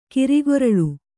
ಕಿರಿಗೊರಳು ♪ kirigoraḷu noun (noun) the voice that is thin, piercing; shrill voice.